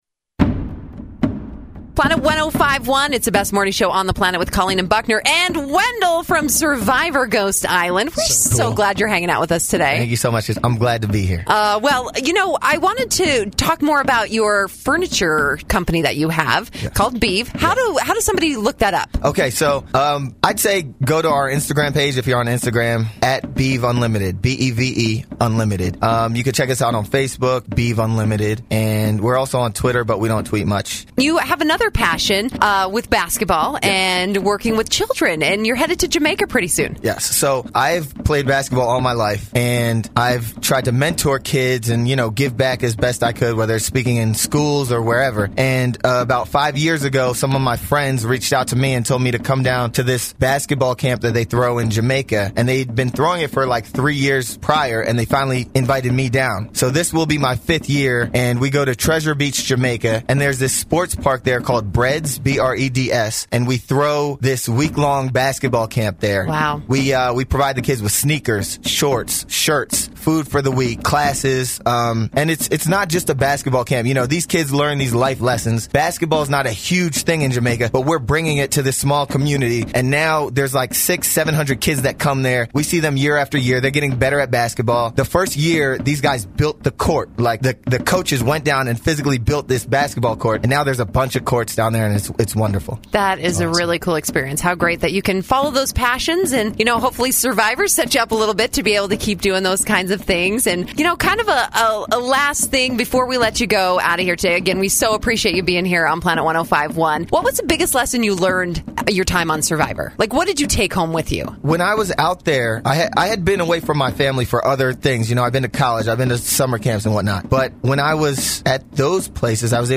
Winner of Survivor Ghost Island, Wendell Holland, stopped by the Planet 105.1 studios on Friday, July 13th to hang out with The Best Morning Show on the Planet!
13 Jul Interview with The Winner of Survivor Ghost Island